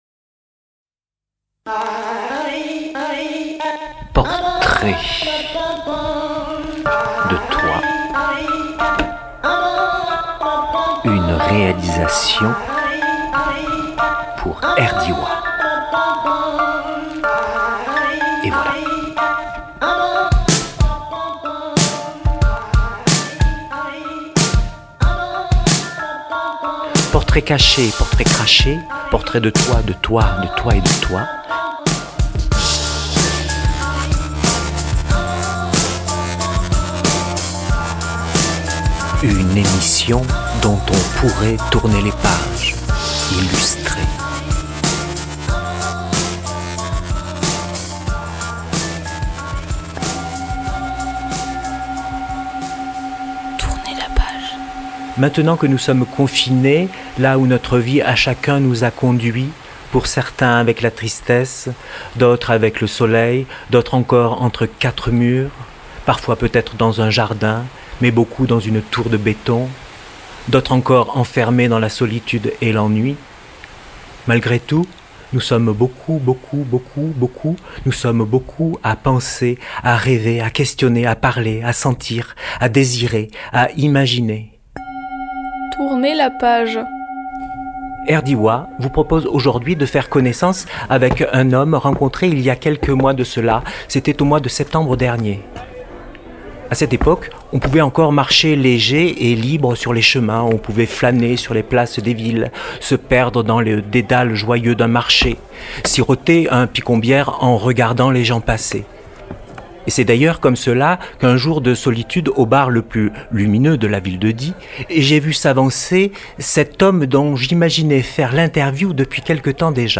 Reportages Portrait de toi